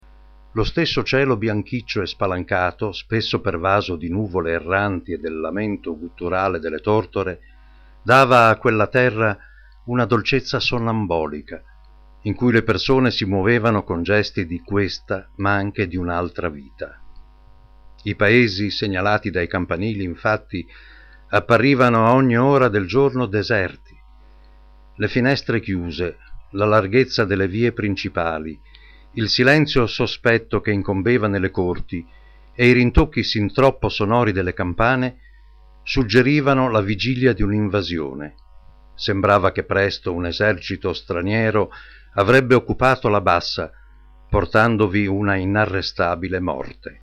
Sprecher italienisch. Età della voce: da 50 a 70 utilizzo: doppiaggio, telecomunicati, radiocomunicati, voiceover.
Sprechprobe: Industrie (Muttersprache):
Italian voice over artist.